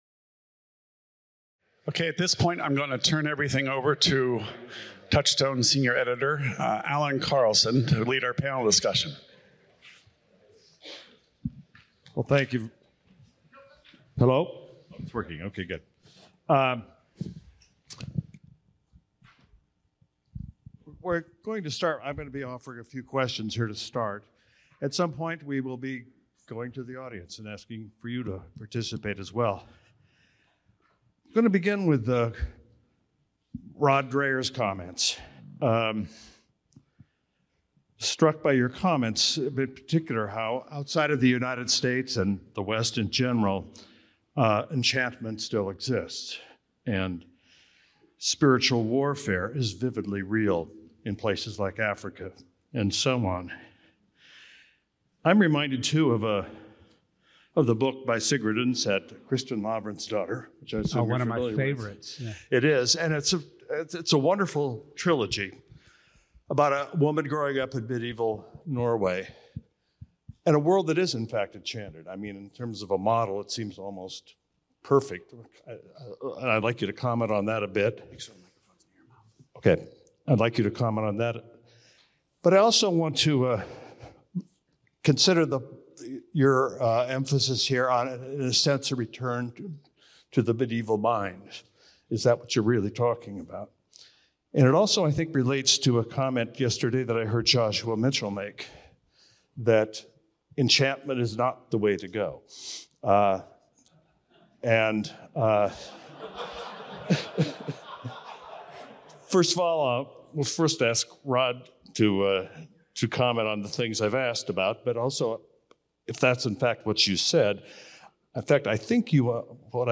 Closing Panel Discussion
2022-panel-discussion.mp3